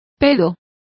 Complete with pronunciation of the translation of fart.